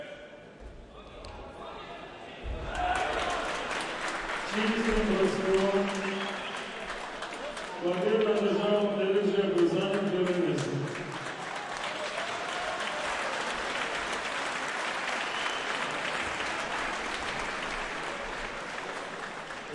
描述：体育摔跤“KURESH”欢呼掌声体育场
Tag: 体育场 掌声 摔跤 体育 欢呼声